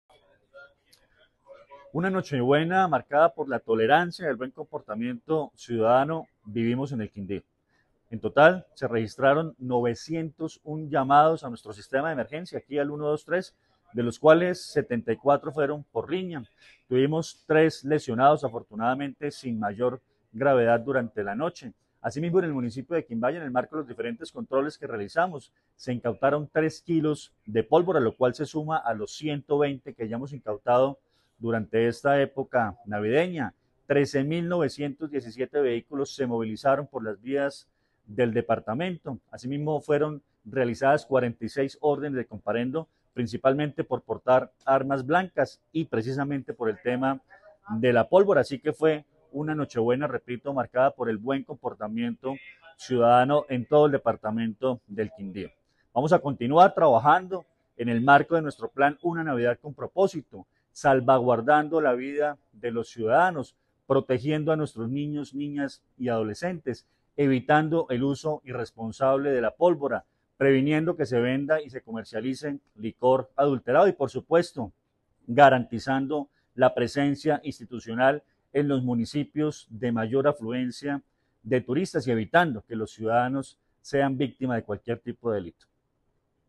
Comandante de la Policía del Quindío, Coronel Luis Fernando Atuesta Zarate